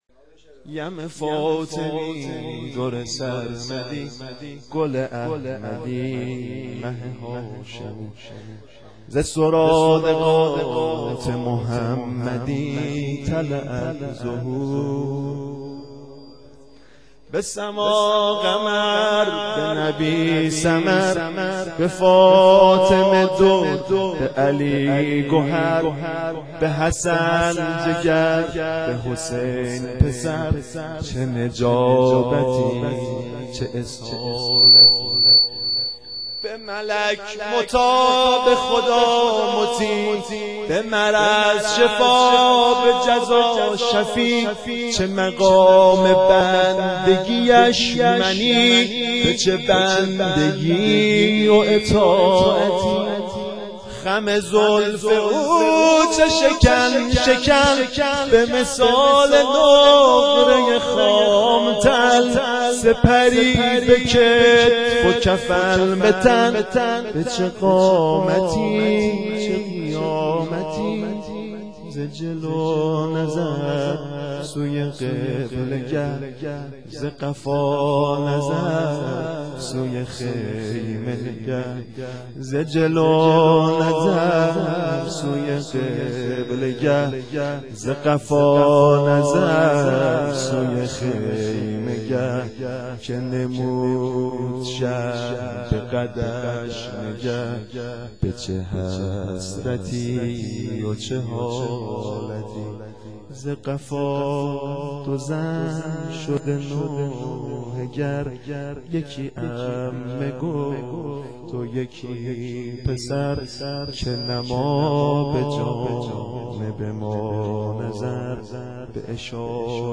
مدیحه سرایی
شام میلاد حضرت علی اکبر 1392